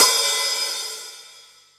paiste hi hat7 open.wav